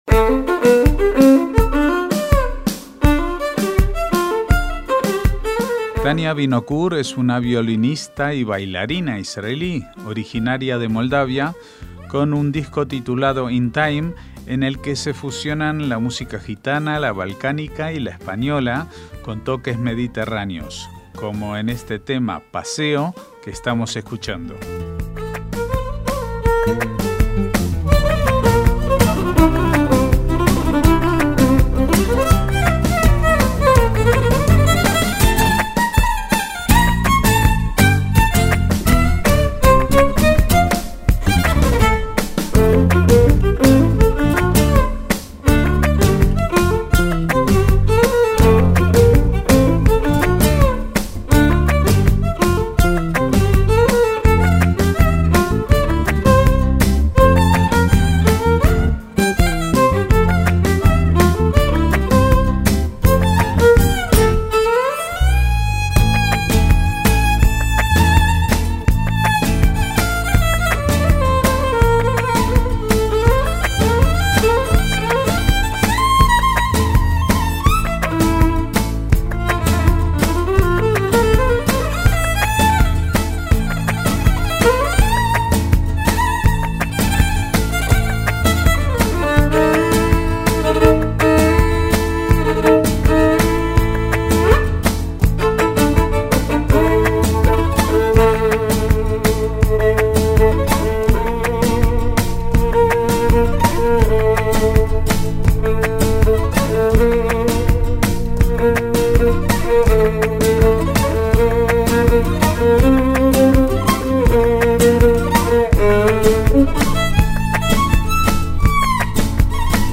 MÚSICA ISRAELÍ
bajo
guitarras
percusiones